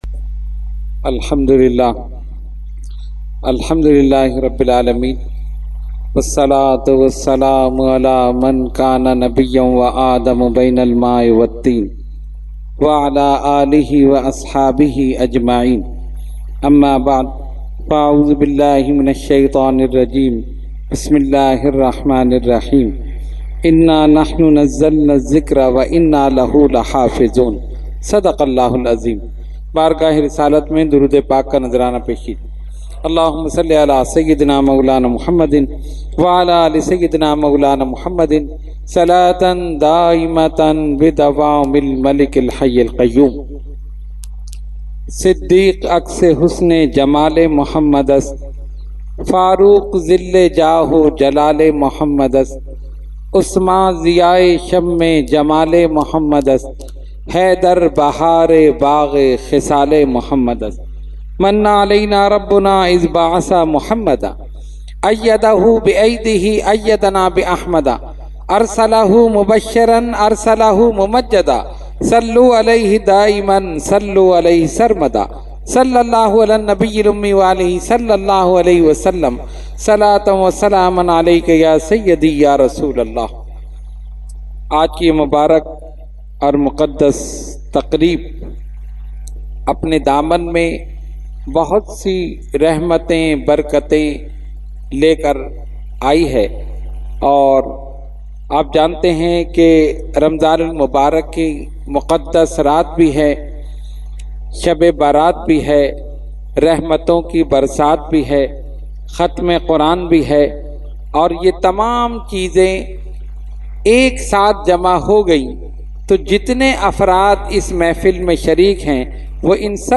Category : Speech | Language : UrduEvent : Khatmul Quran 2020